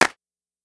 DrClap12.wav